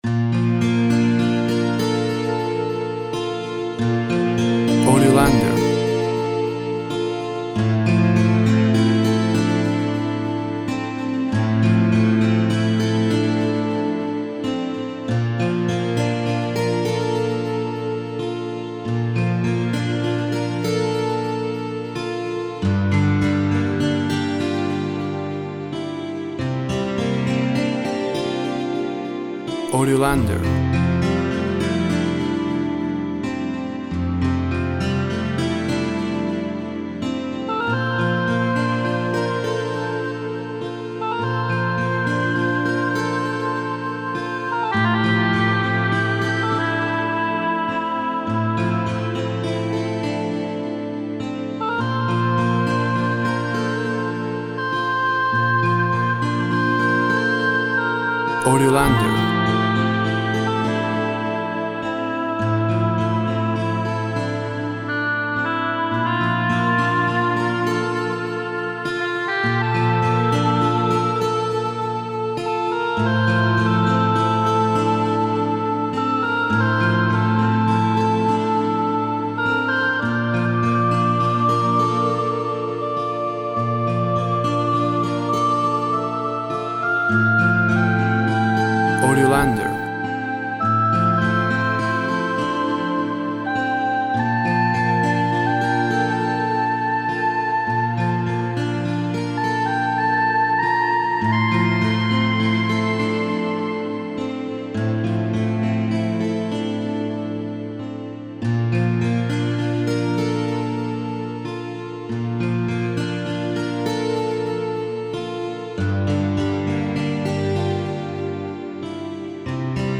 Guitar, oboe, and soft trumpet create a quiet mood.
Tempo (BPM) 100